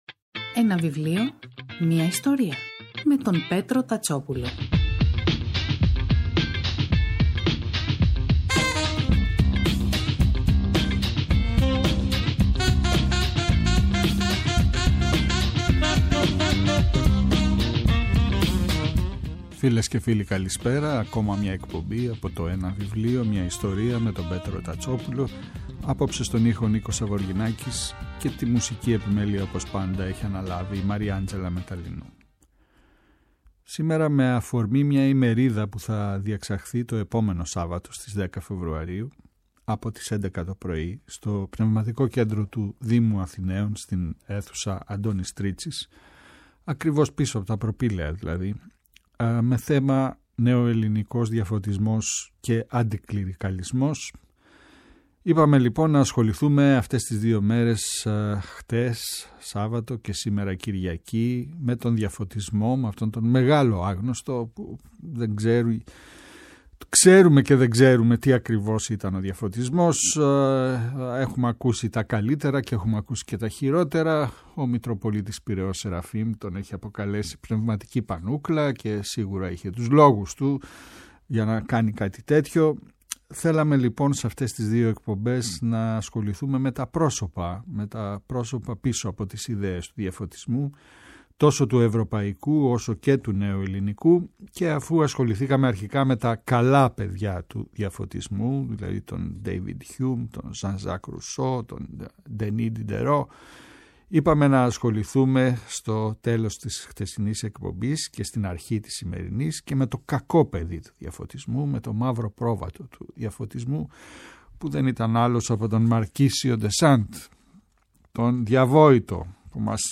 Κάθε Σάββατο και Κυριακή, στις 5 το απόγευμα στο Πρώτο Πρόγραμμα της Ελληνικής Ραδιοφωνίας ο Πέτρος Τατσόπουλος , παρουσιάζει ένα συγγραφικό έργο, με έμφαση στην τρέχουσα εκδοτική παραγωγή, αλλά και παλαιότερες εκδόσεις.